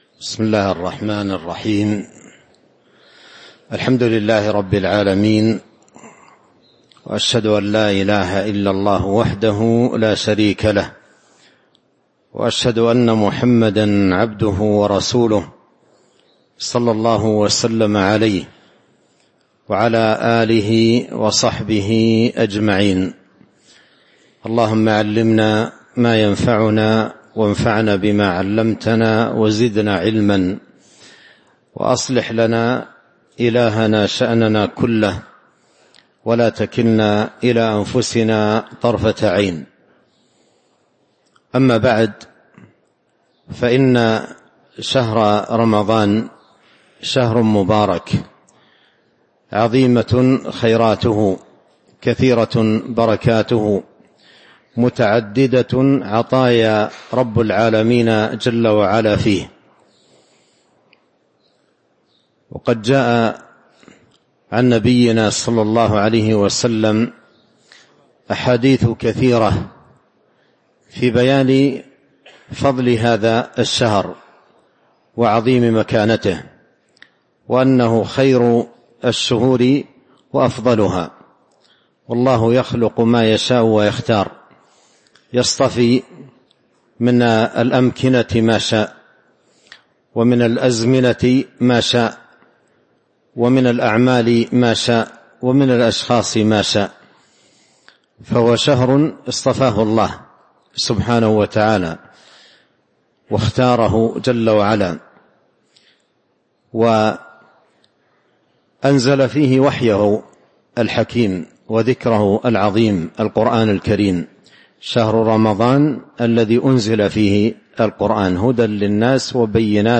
تاريخ النشر ١ رمضان ١٤٤٥ هـ المكان: المسجد النبوي الشيخ: فضيلة الشيخ عبد الرزاق بن عبد المحسن البدر فضيلة الشيخ عبد الرزاق بن عبد المحسن البدر مدرسة الصيام